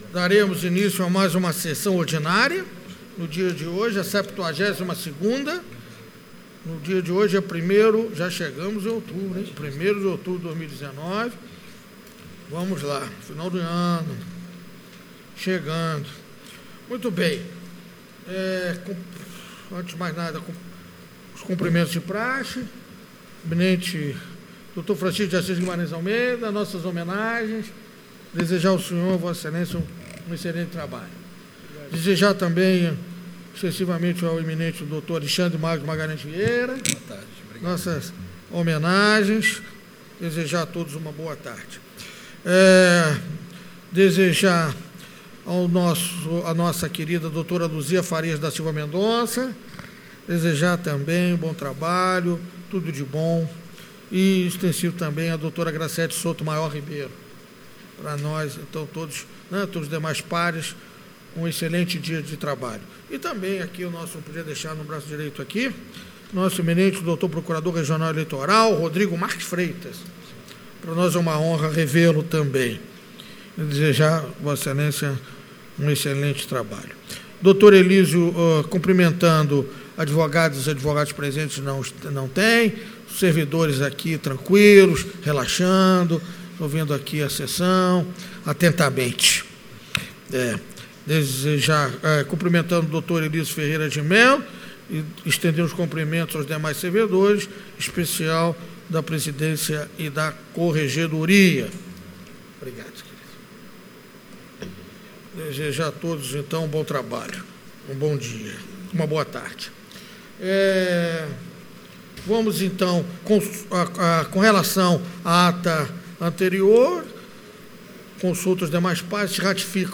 Áudio da Sessão de julgamento 01/10/2019